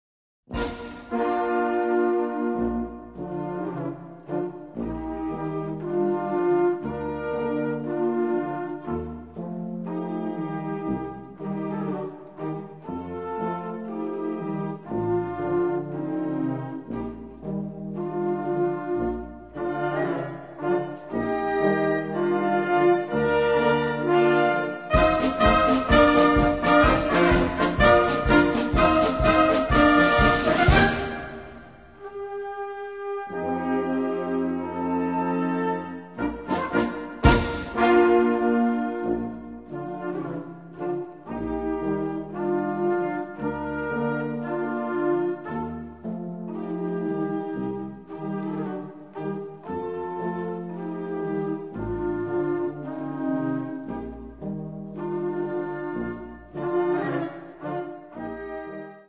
Kategorie Blasorchester/HaFaBra
Unterkategorie Musik aus der Romantik (1820-1900)
Besetzung Ha (Blasorchester)